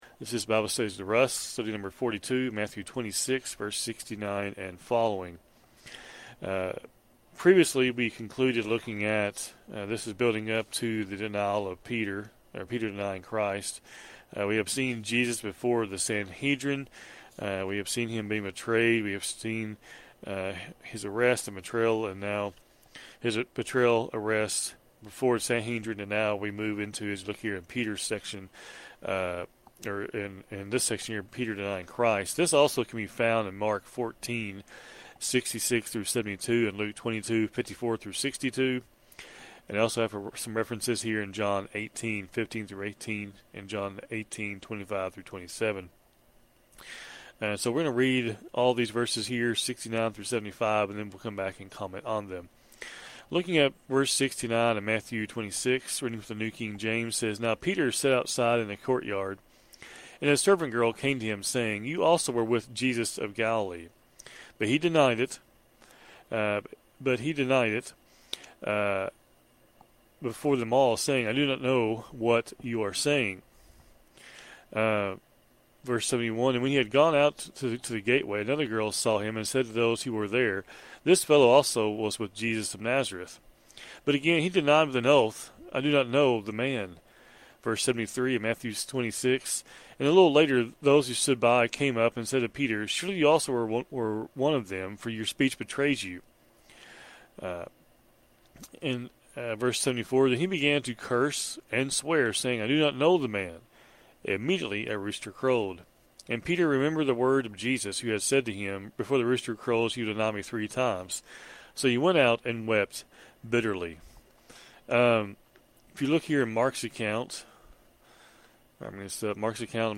Program Info: Live program from the TGRN studio in Mount Vernon, TX